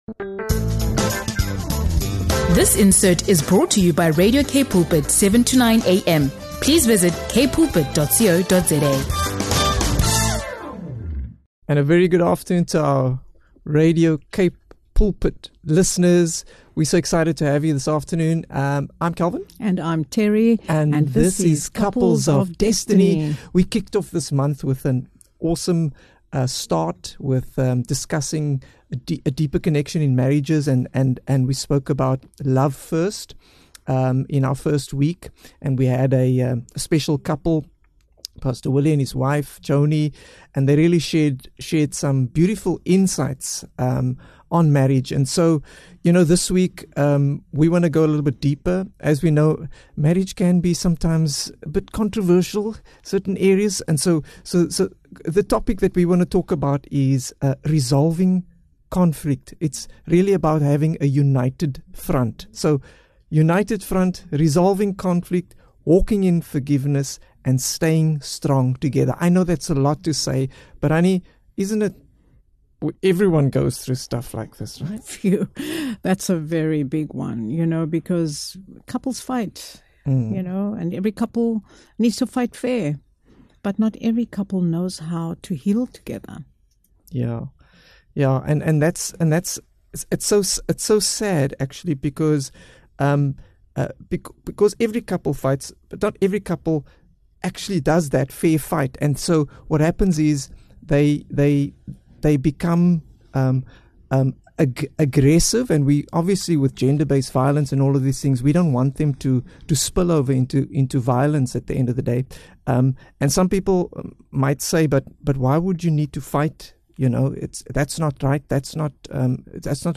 Don’t miss this powerful conversation that equips you to stand together even in the face of challenges.